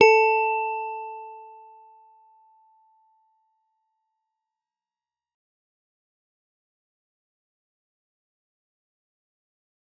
G_Musicbox-A4-f.wav